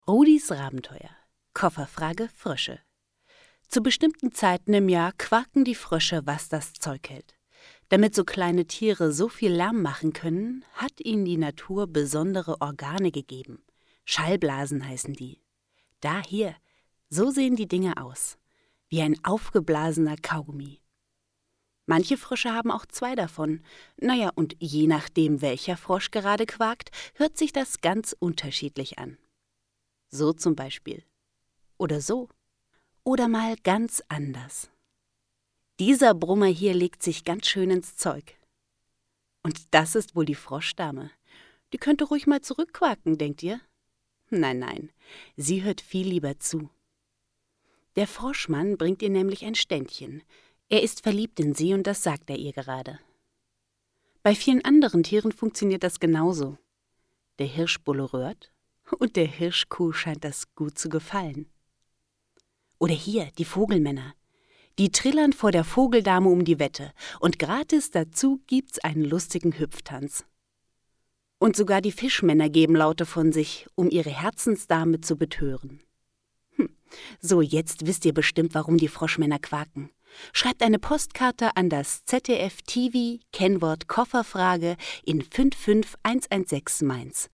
Neue junge Stimme, gut für Hörspiele und Computerspiele geeignet.
Sprechprobe: Sonstiges (Muttersprache):